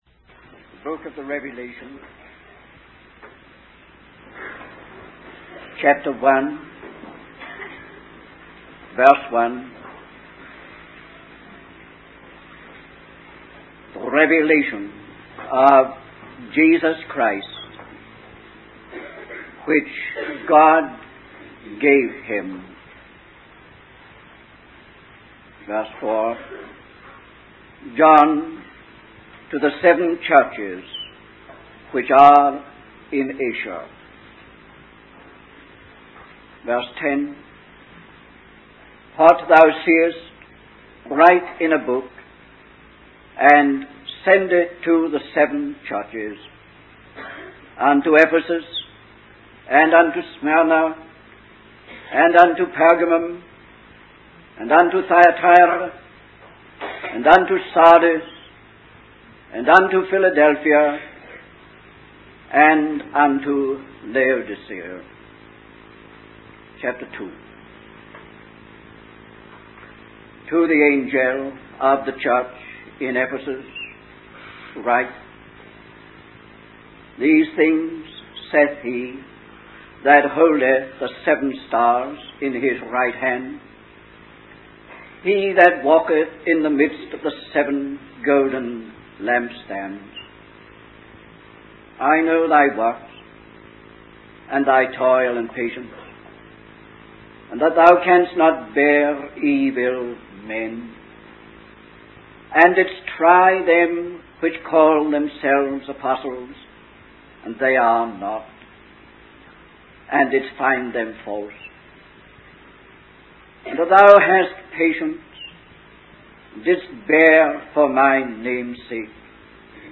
In this sermon, the speaker discusses the book of Revelation and its role in revealing Jesus Christ.